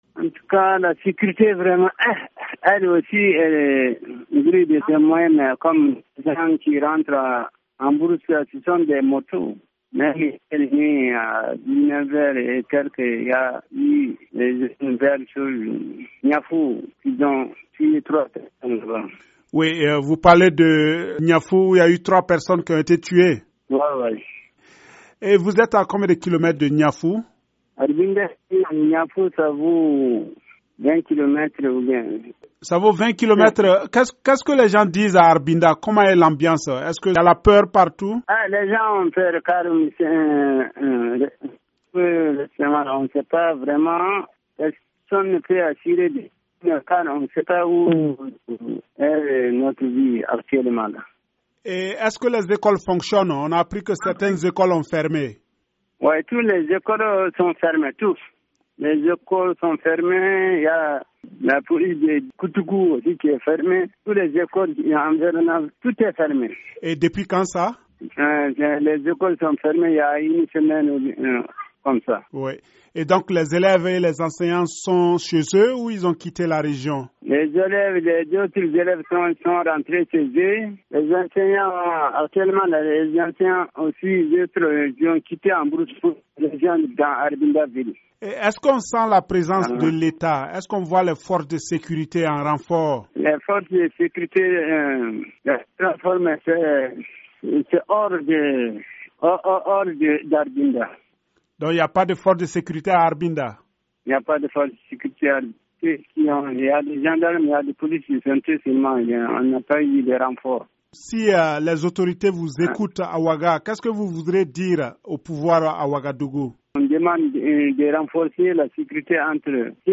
Temoignage d'un habitant de la ville d'Arbinda